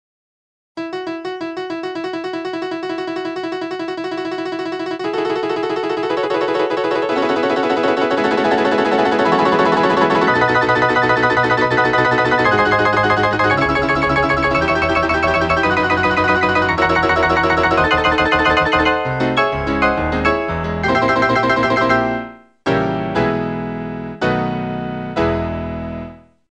CAPACITY TEST
If it doesn't, the first chord won't stop sounding when the next chord begins.
If the chords seem 'blur' into each other, the automatic sustain pedal isn't turning 'off' fast enough, and needs to be adjusted or repaired.